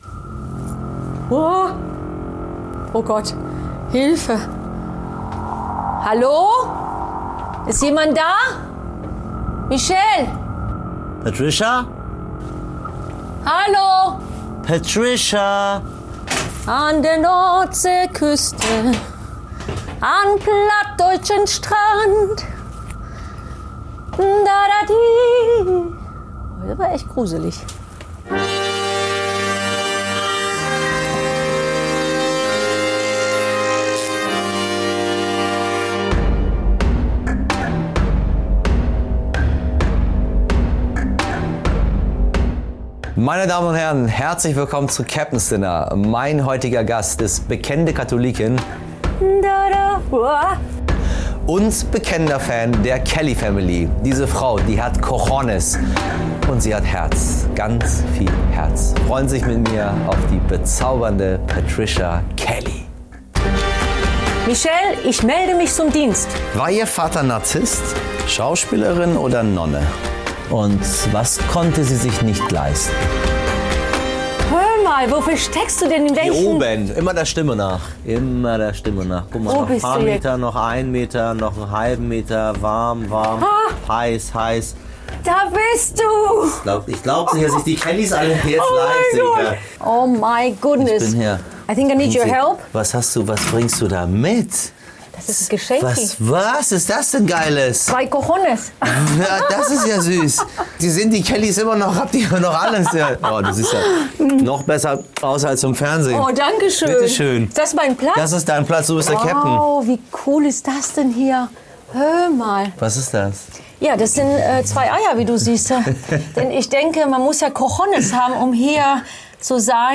Bei Michel Abdollahi im U-Boot ist Sängerin Patricia Kelly zu Gast.